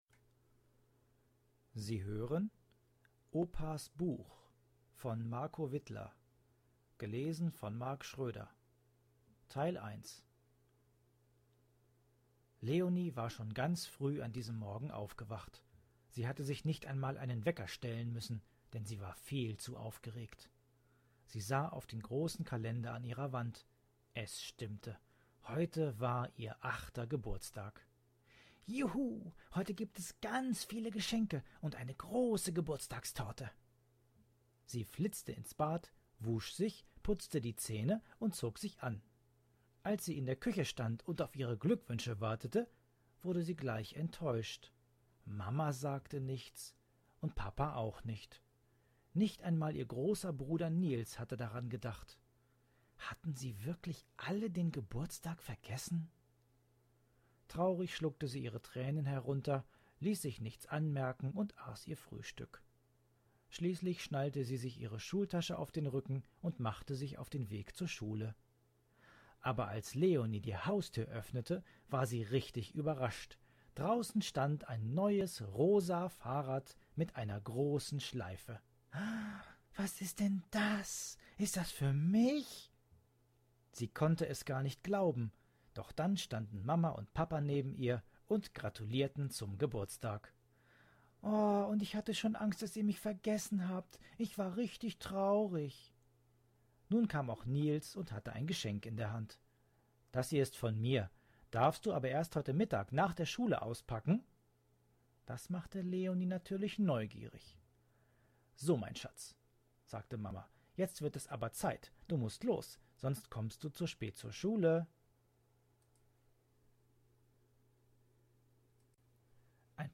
Hörgeschichte 06 – Opas Buch